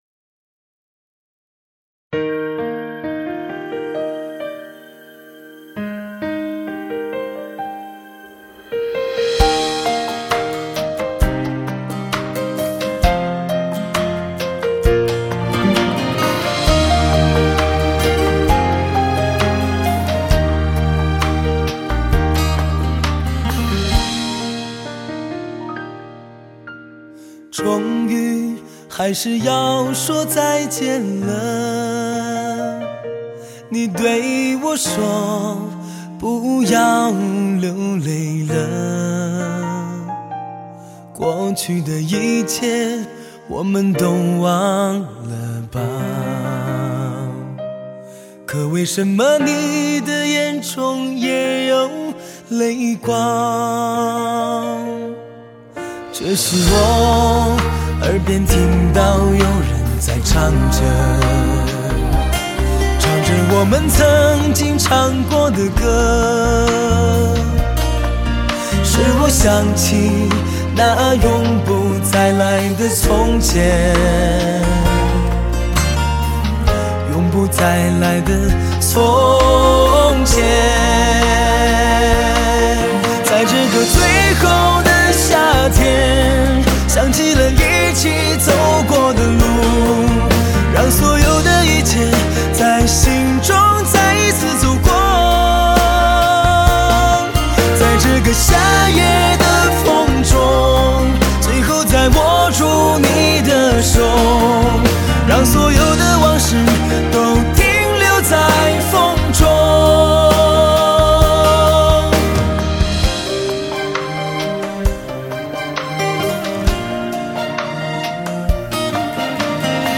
新专辑延续其一贯的风格，以伤感爱情歌曲为主打，深情磁性的声线，表达出现实的情感，让你回忆起爱情过程中的点点滴滴。